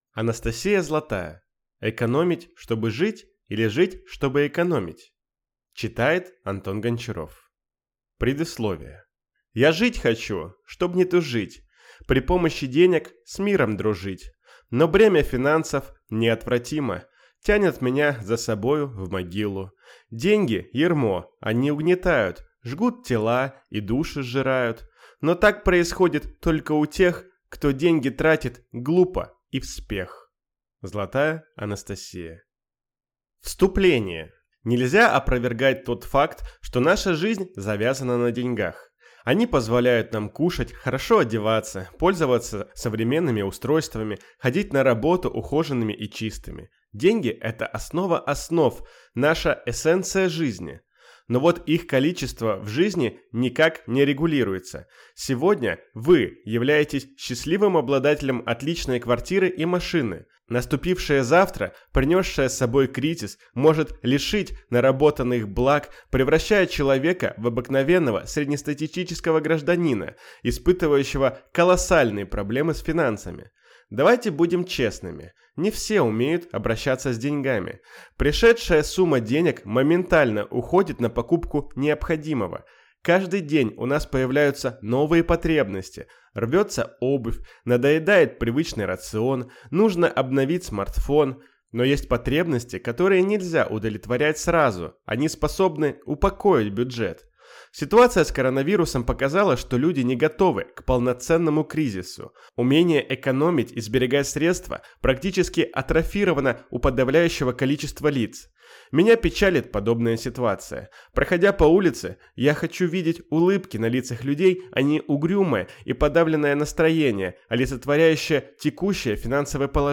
Аудиокнига Экономить, чтобы жить или жить, чтобы экономить?